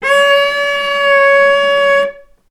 healing-soundscapes/Sound Banks/HSS_OP_Pack/Strings/cello/ord/vc-C#5-ff.AIF at b3491bb4d8ce6d21e289ff40adc3c6f654cc89a0
vc-C#5-ff.AIF